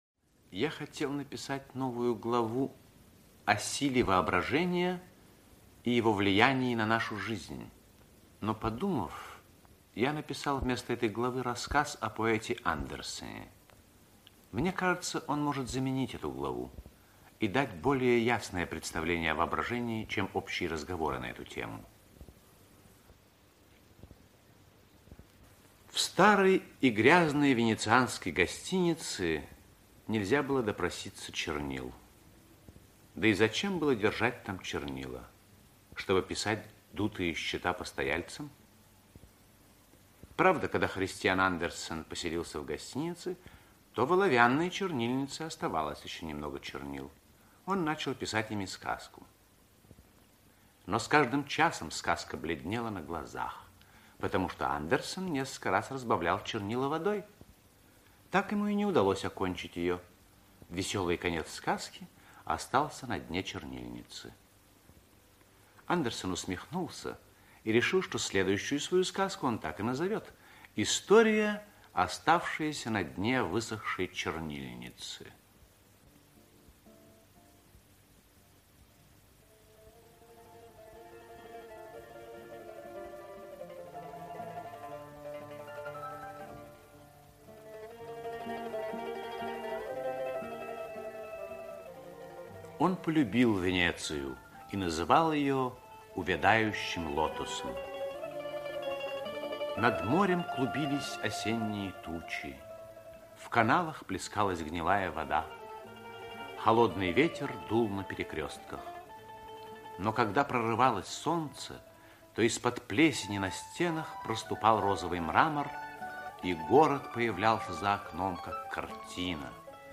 Ночной дилижанс - аудио рассказ Паустовского - слушать